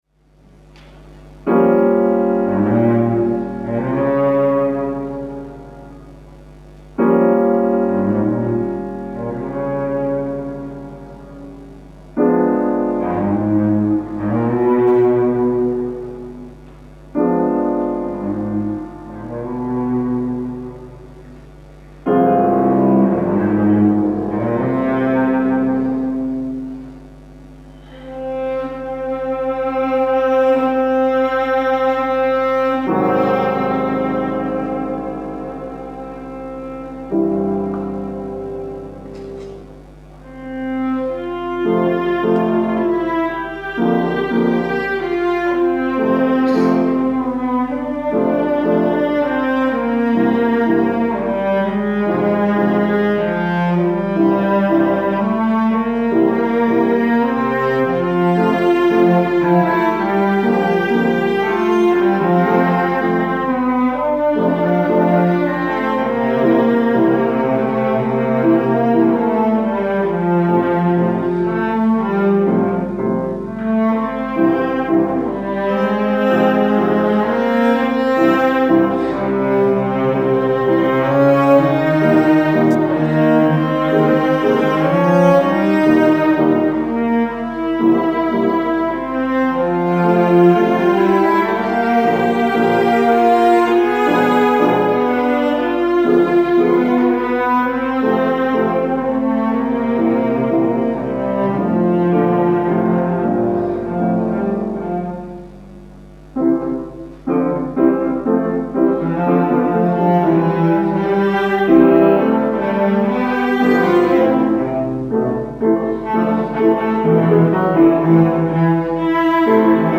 Anläßlich der Preisverleihung des Lessingpreises für Kritik an Alexander Kluge in der Herzog August Bibliothek in Wolfenbüttel, nutzt Herr Kluge die Gelegenheit für einen musikalischen Vergleich.
St. Petersburger Cello Duo